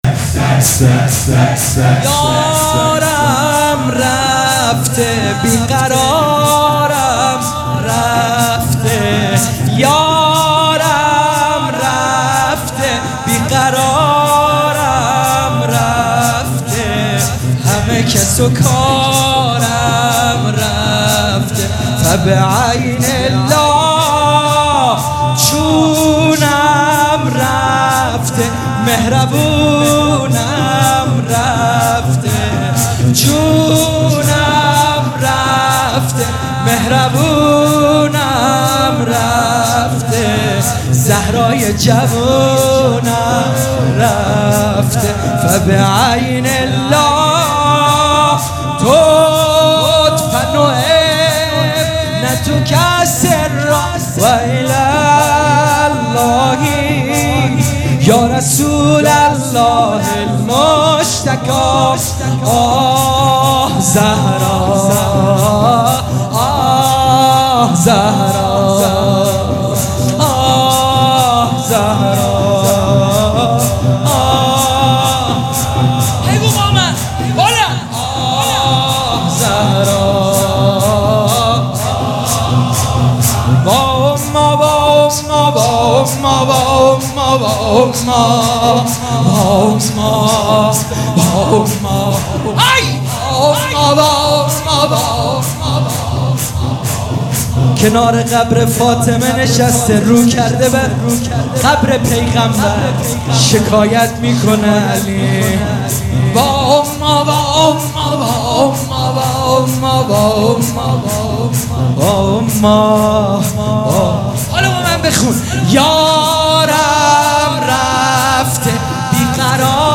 شب سوم مراسم عزاداری فاطمیه دوم ۱۴۴۴
شور